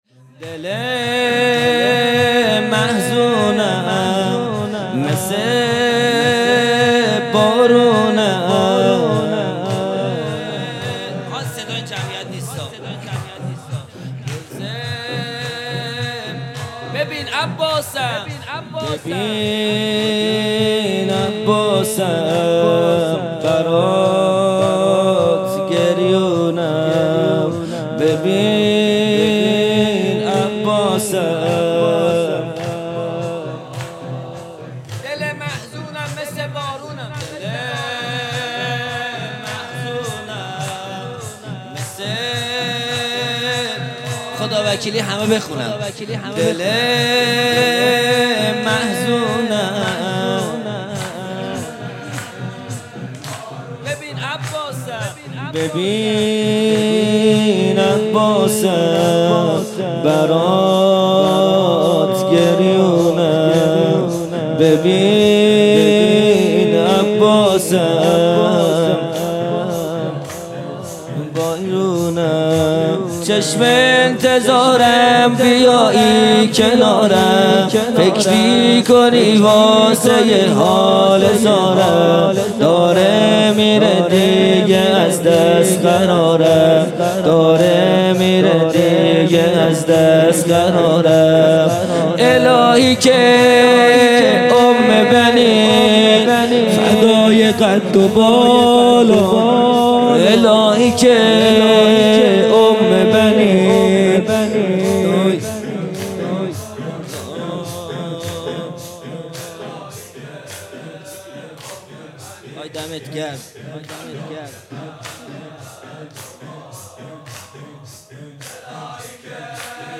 خیمه گاه - هیئت بچه های فاطمه (س) - زمینه | دل محزونم، مث بارونم | 23 دی 1400
جلسۀ هفتگی